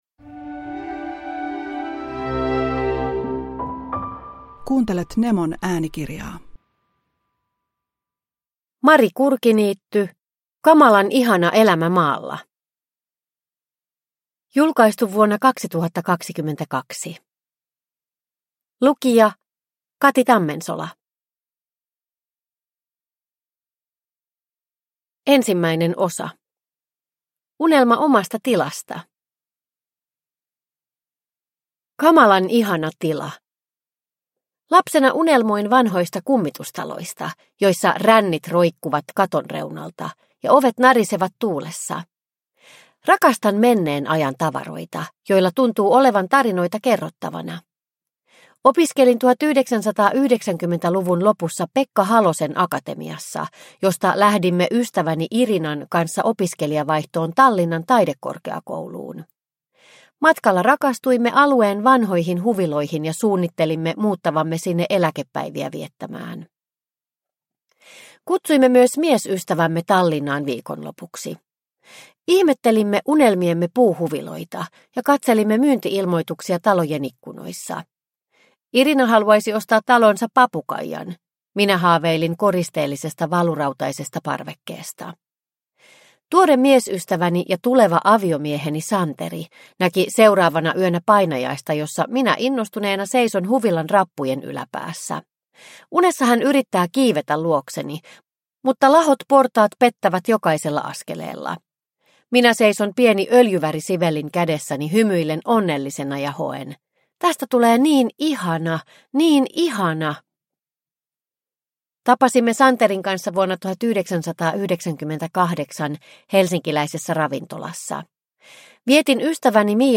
Kamalan ihana elämä maalla – Ljudbok